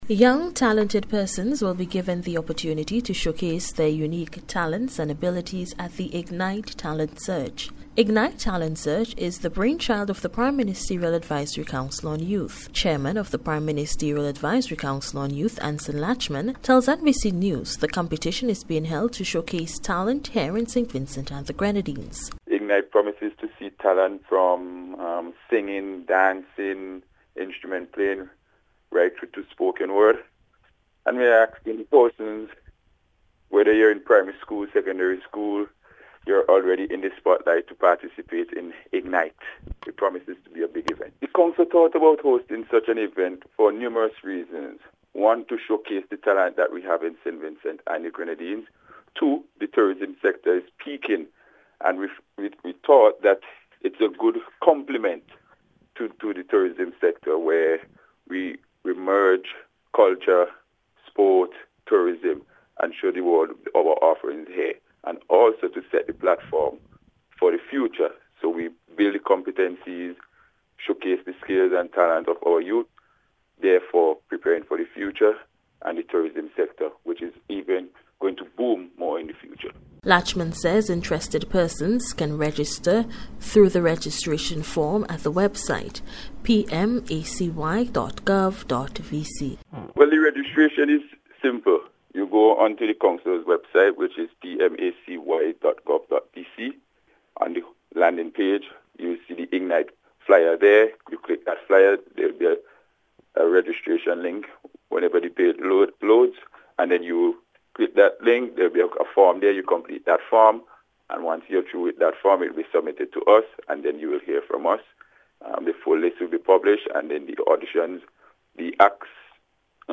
IGNITE-SPECIAL-REPORT.mp3